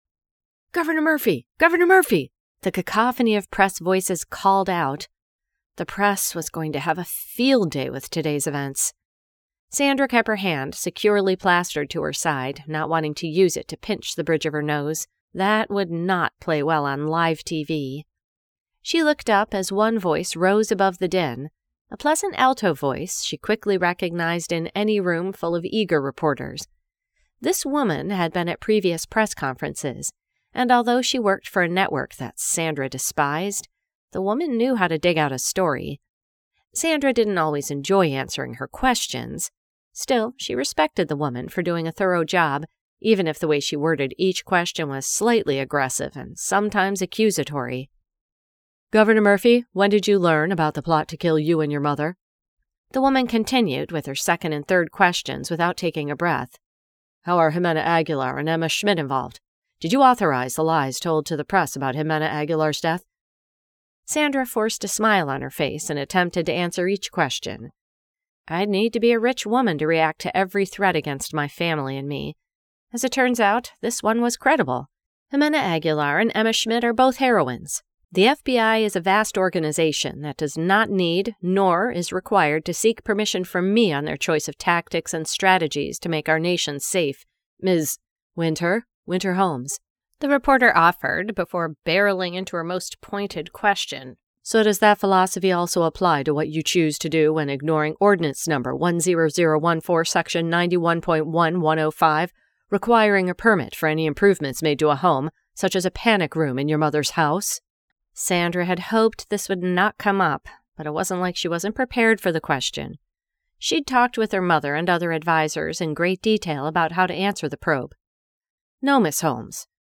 Politics of Love by Annette Mori San Diego Trilogy-Book 2 [Audiobook]